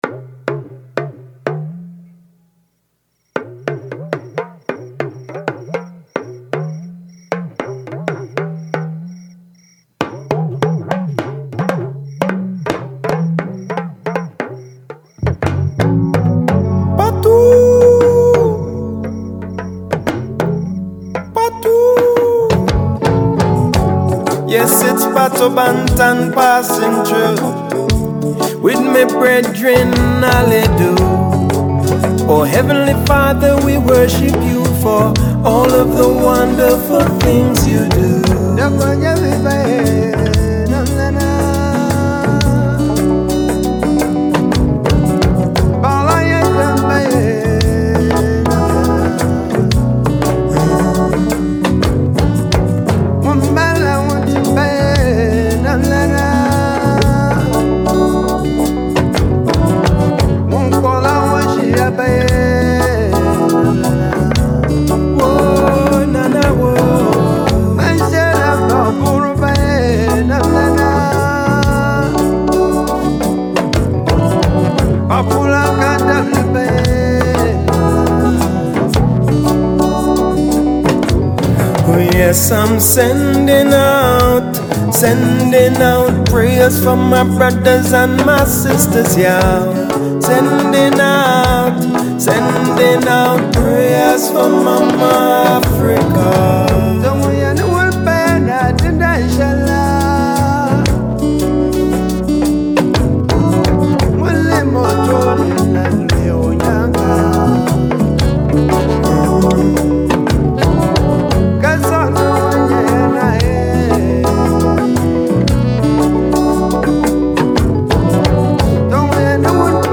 Talking Drum from Ghana
The Luan from Northern Ghana is an hour-glass shaped talking drum played with a stick. The drummer squeezes and releases the cords that run between its two drumheads to change the pitch.
– Drum heads: goat skin